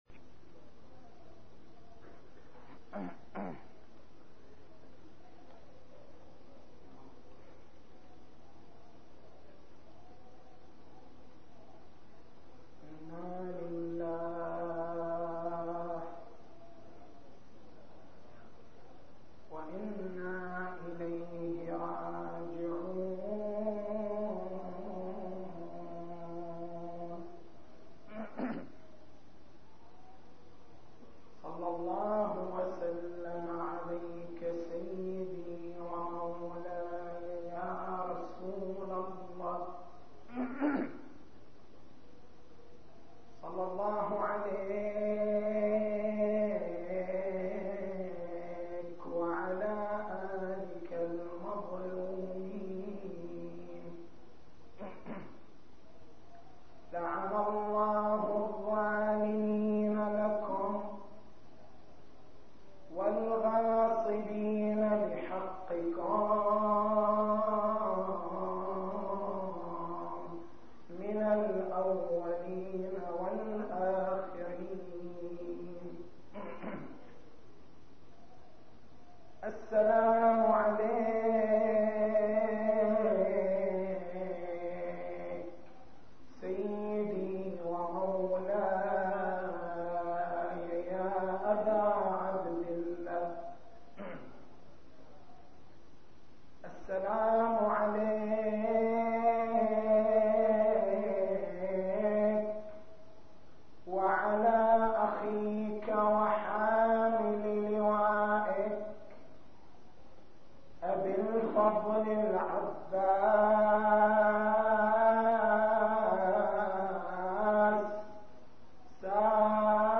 تاريخ المحاضرة: 07/01/1423 نقاط البحث: نظرة الإسلام للهو عوامل اللهو والعبثية أساليب اللهو والترفيه الرشيد التسجيل الصوتي: تحميل التسجيل الصوتي: شبكة الضياء > مكتبة المحاضرات > محرم الحرام > محرم الحرام 1423